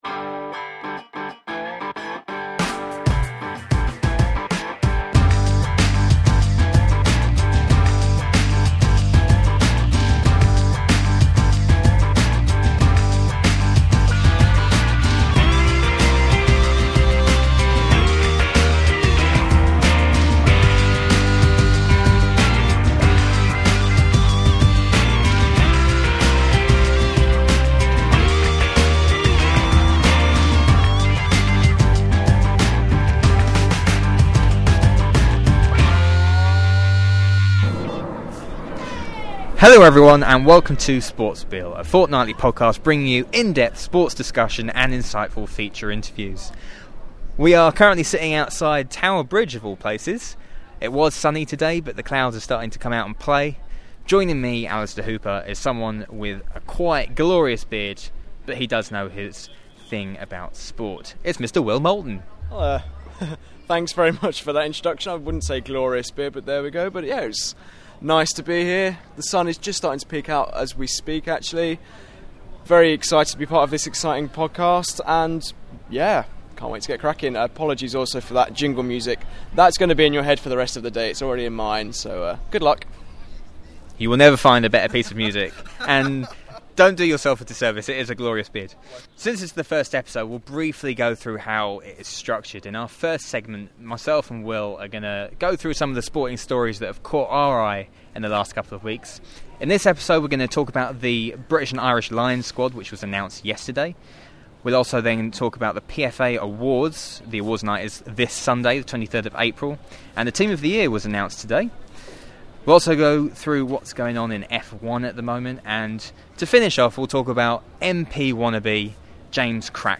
a podcast full of sporting discussion and feature interviews.